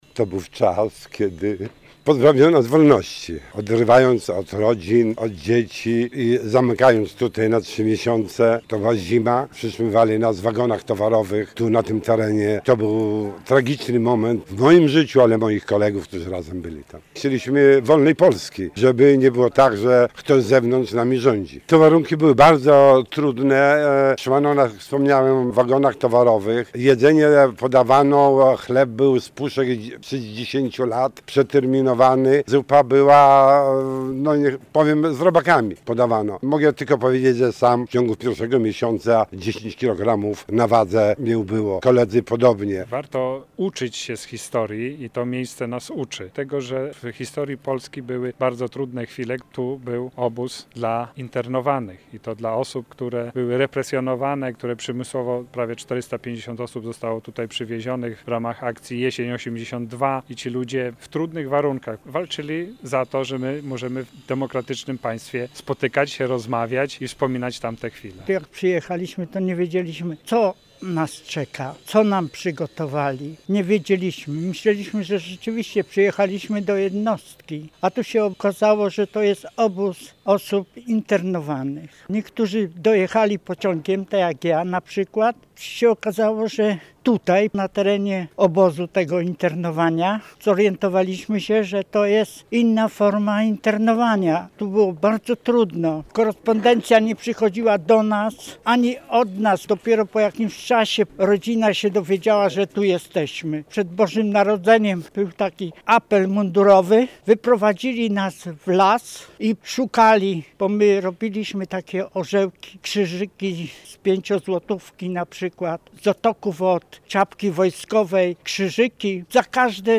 Odsłonięcie tablicy upamiętniającej internowanych - relacja